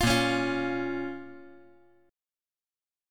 Bbm Chord
Listen to Bbm strummed